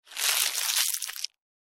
دانلود آهنگ پلاستیک از افکت صوتی اشیاء
دانلود صدای پلاستیک از ساعد نیوز با لینک مستقیم و کیفیت بالا
جلوه های صوتی